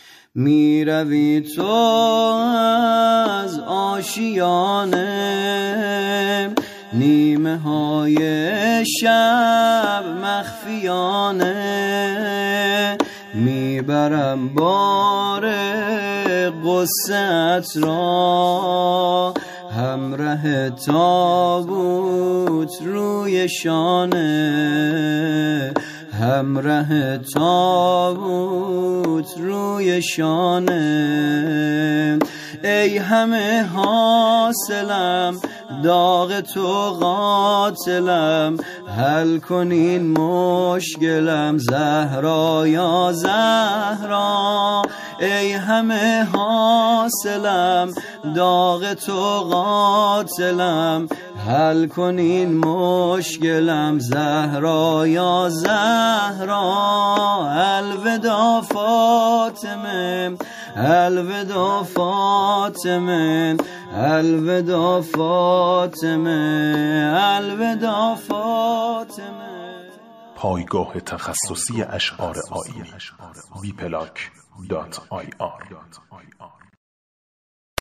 فاطمه الزهرا شام غریبان نوحه